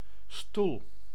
Ääntäminen
Ääntäminen : IPA: [stul] Haettu sana löytyi näillä lähdekielillä: hollanti Käännös Konteksti Ääninäyte Substantiivit 1. chair US UK 2. sitting furniture 3. normally mobile and on four legs 4.